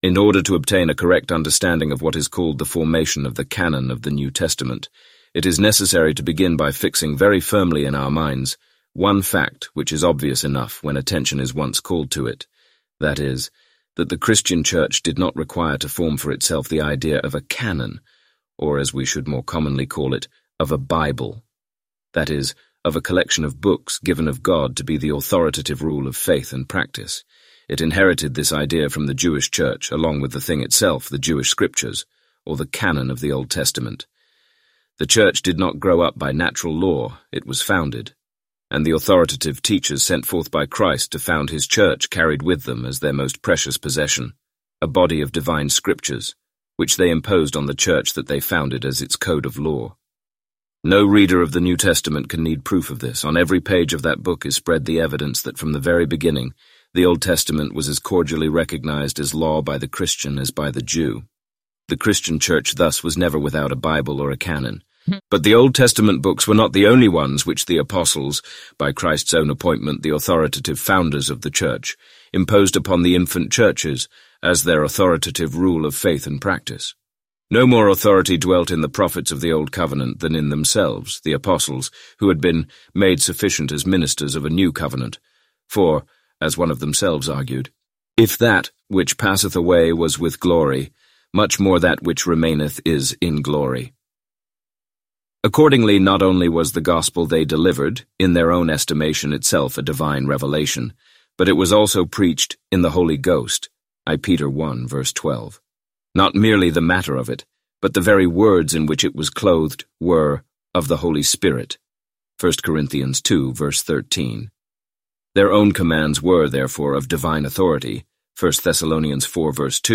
Complete Audiobook